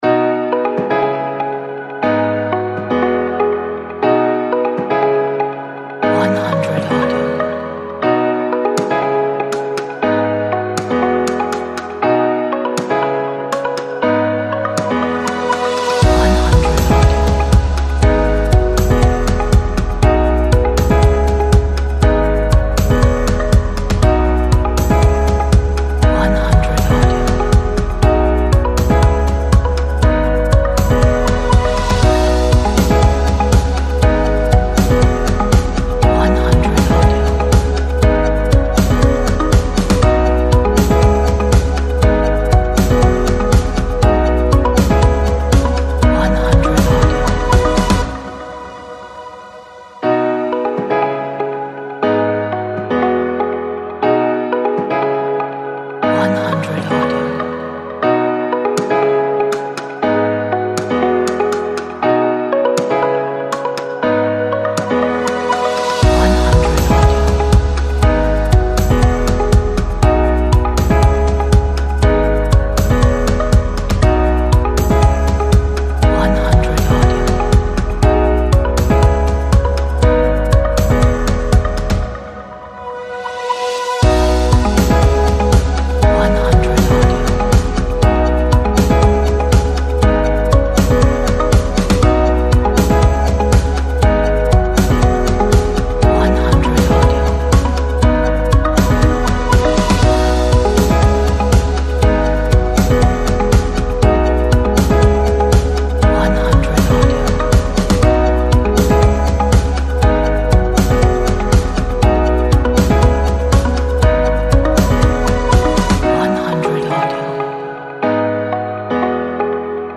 一首鼓舞人心的背景音乐，充满活力和快乐的心情。